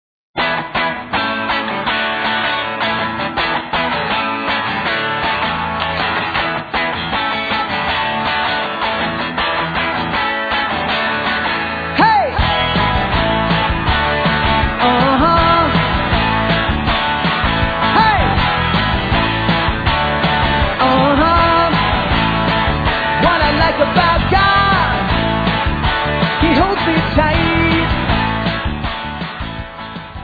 You will love the upbeat music and fun Christian message.